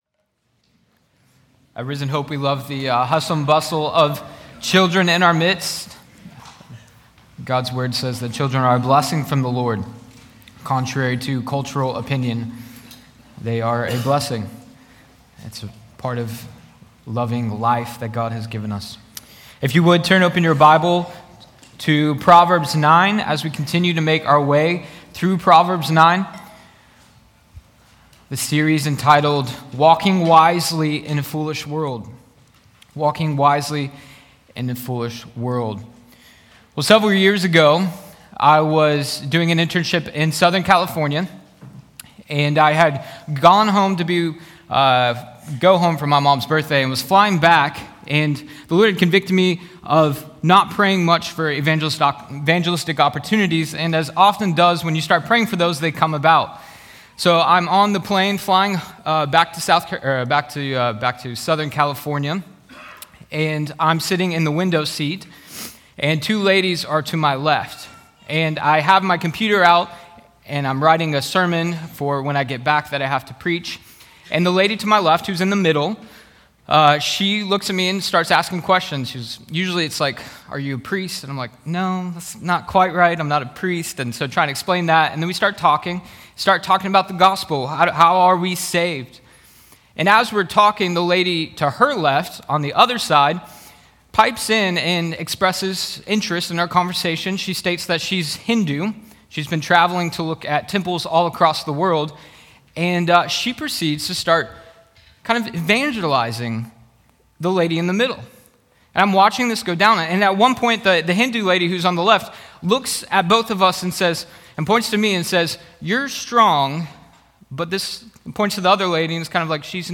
Sunday-Service.mp3